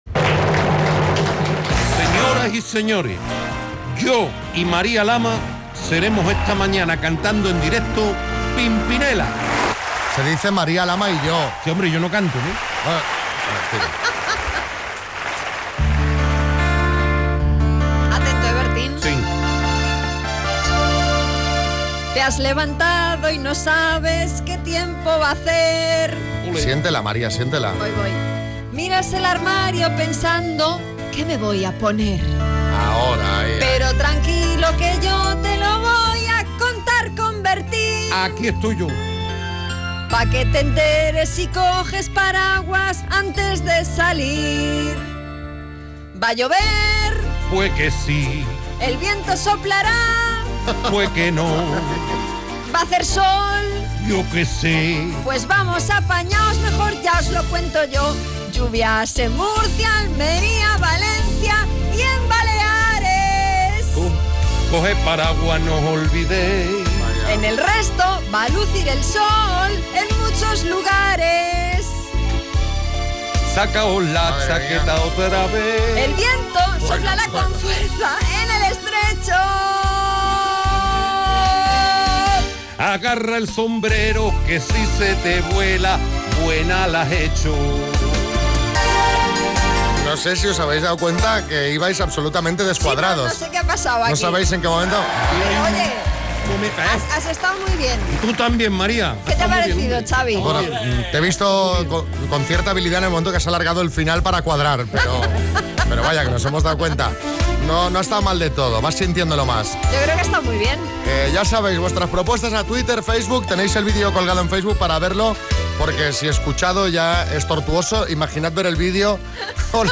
El tiempo a dos voces